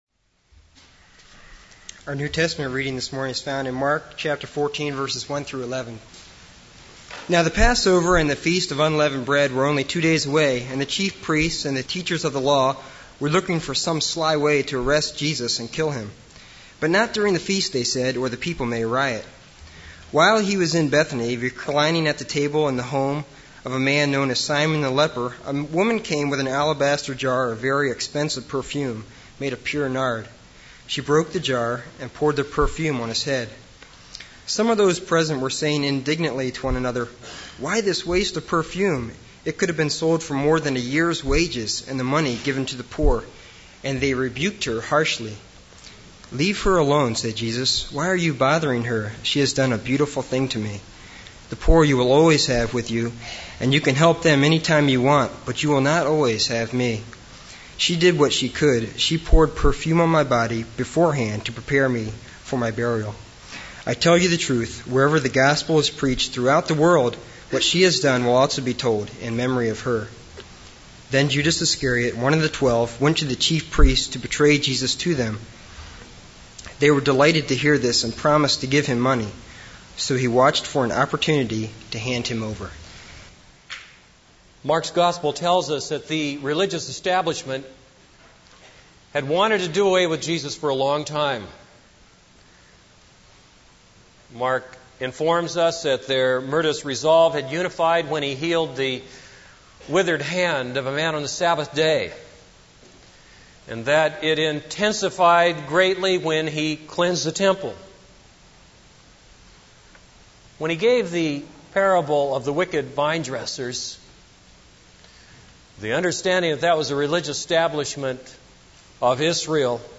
This is a sermon on Mark 14:1-11.